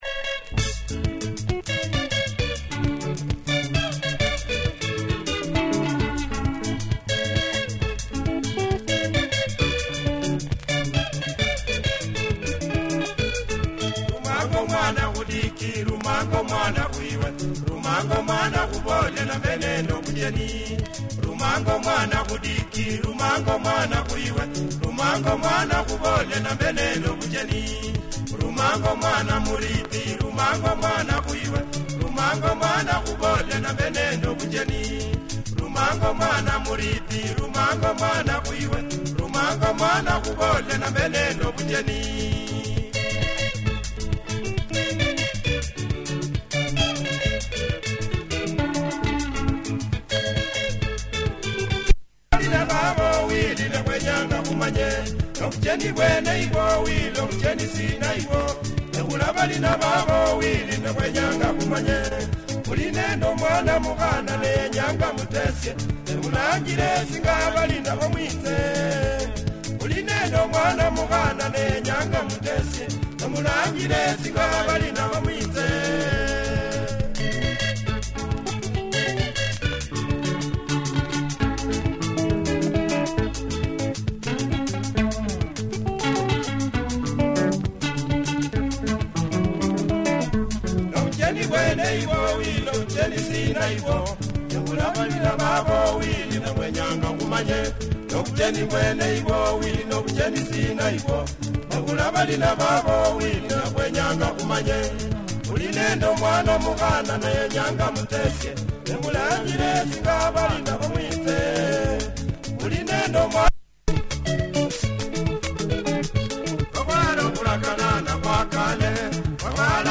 Nice Luhya benga